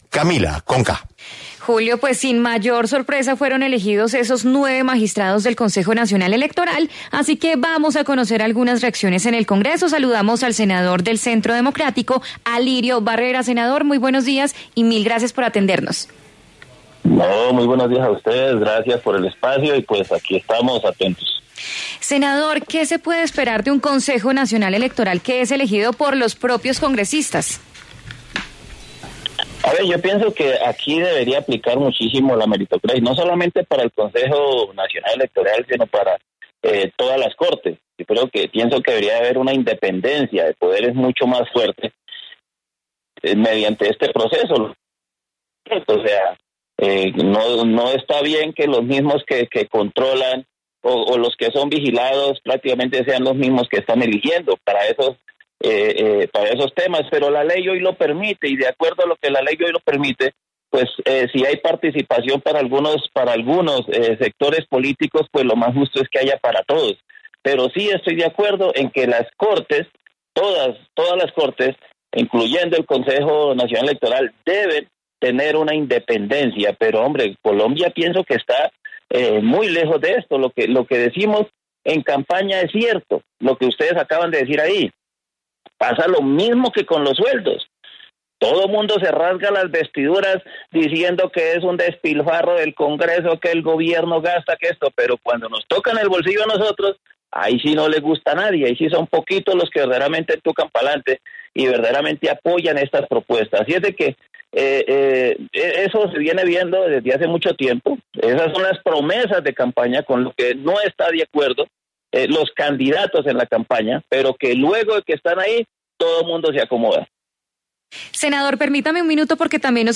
Santiago Osorio, representante a la Cámara, y el senador Alirio Barrera, conversaron en La W sobre la elección de los nueve magistrados del Consejo Nacional Electoral.